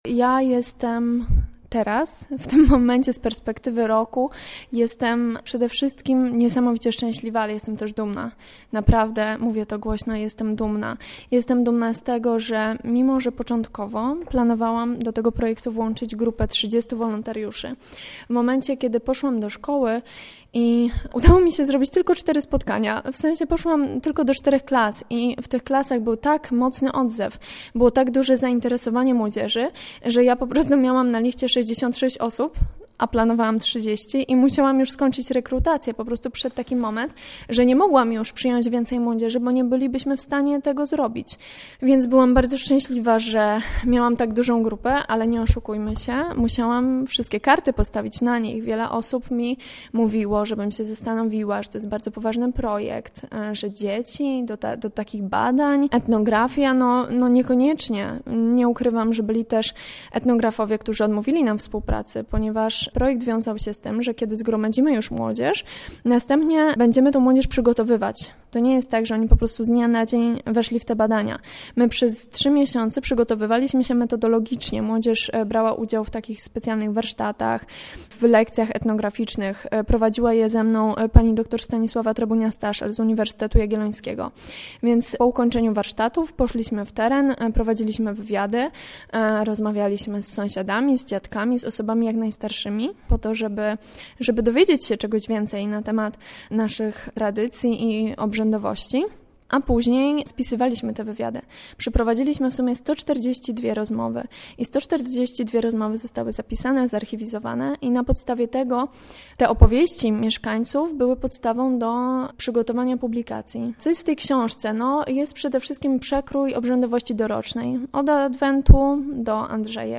Sandomierz: Rozmowa